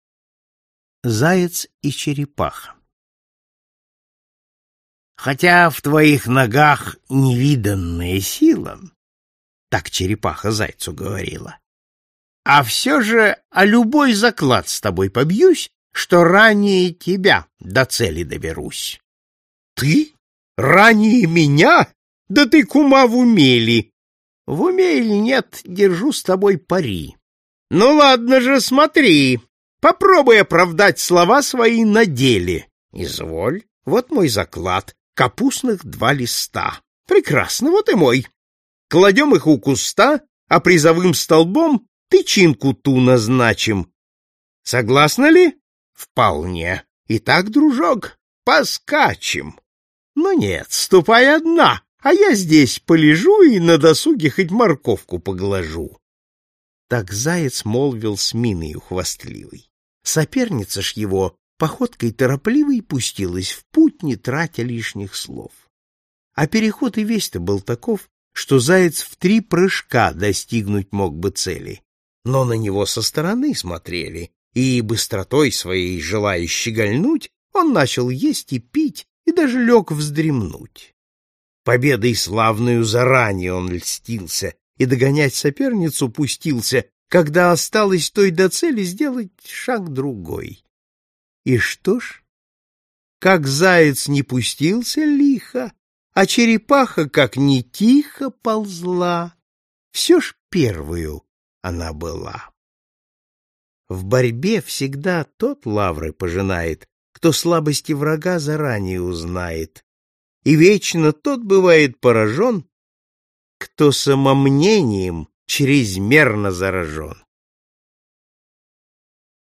Аудиокнига Басни